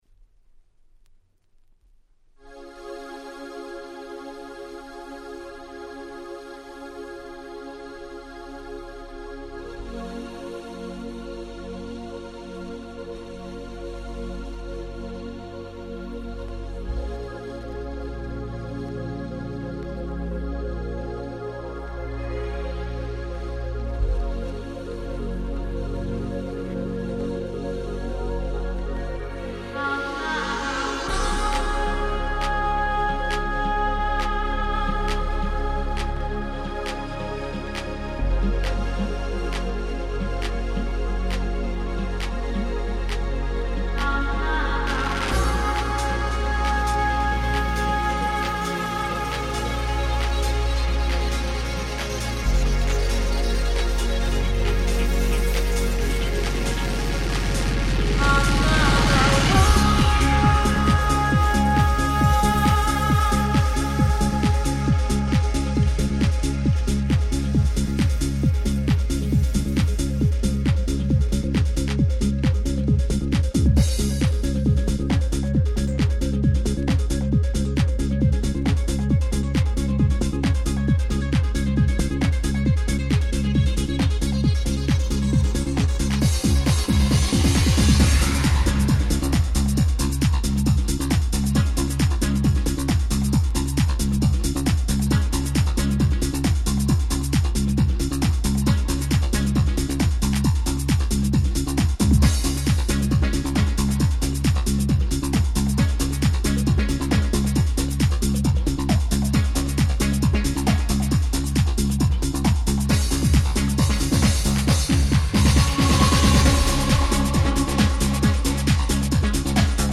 ご存知の通り原曲は壮大なバラードでしたが、こちらはフロア仕様の鬼キャッチーなオネハアレンジ♪